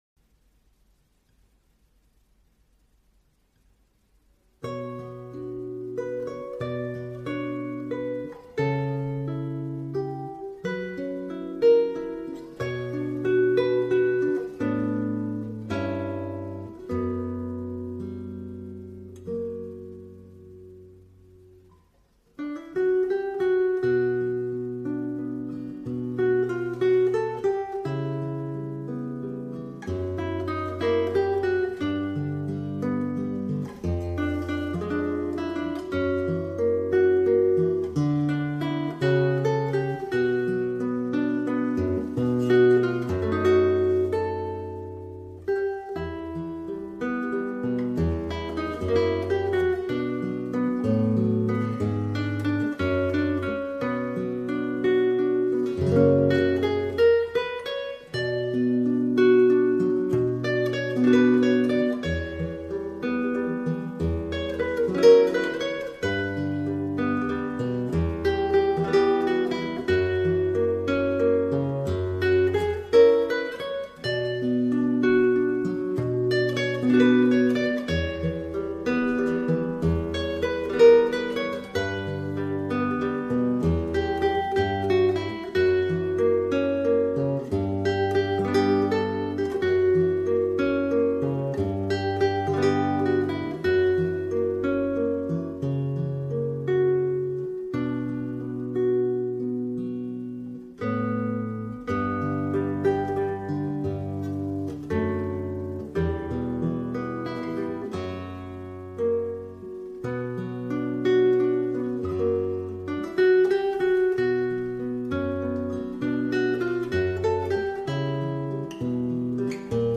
Classical guitar cover